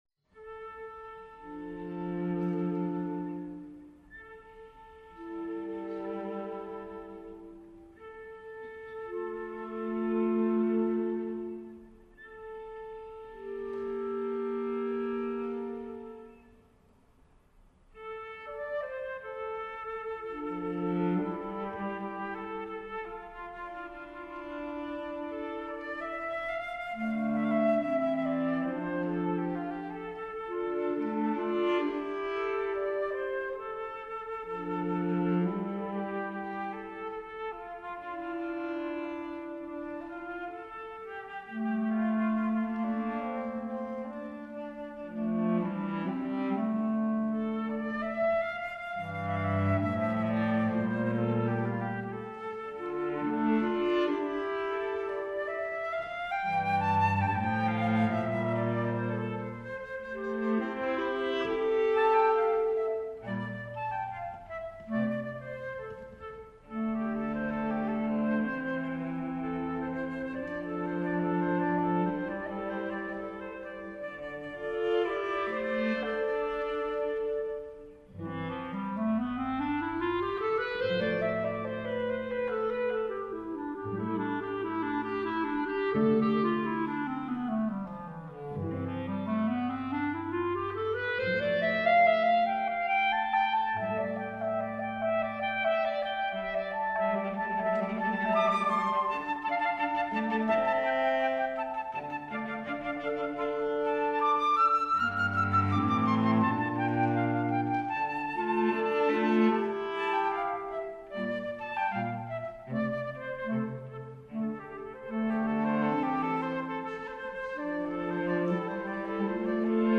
for Flute, Clarinet, and Cello (2016)